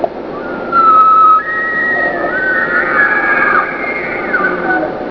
Wildlife Sounds
elk3
elk3.wav